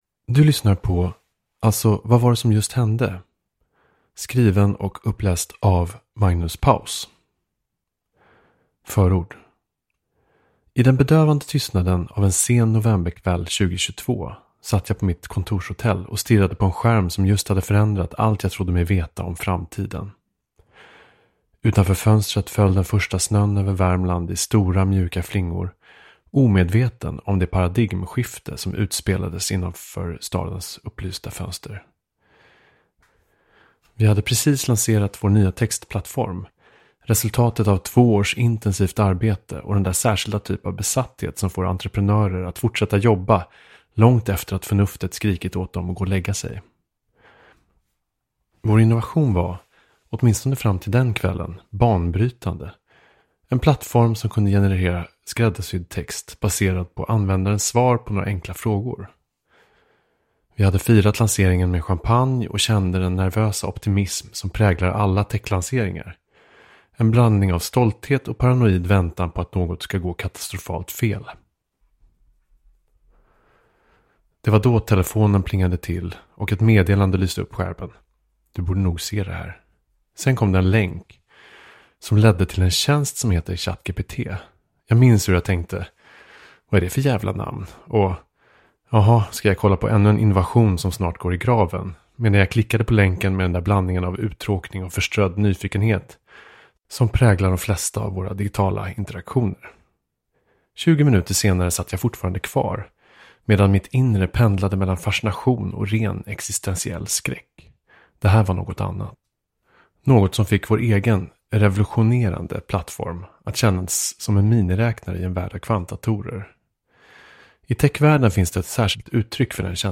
Alltså, vad var det som just hände? – Ljudbok